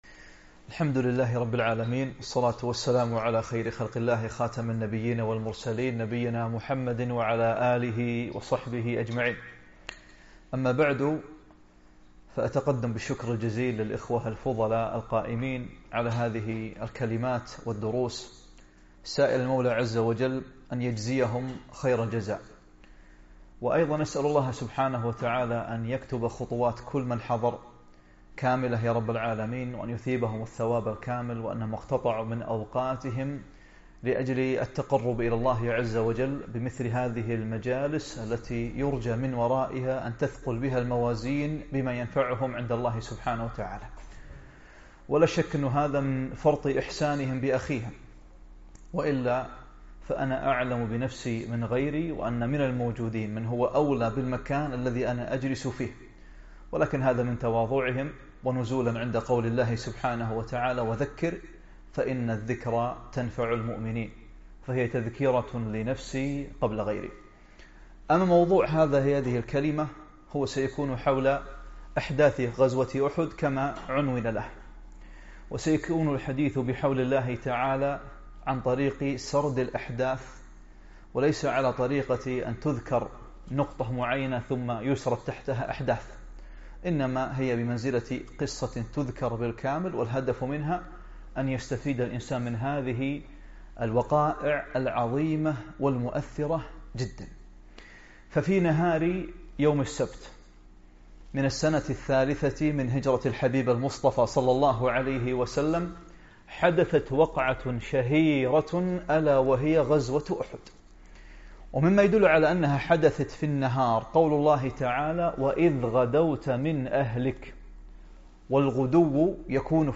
محاضرة - أحداث من غزوة أحد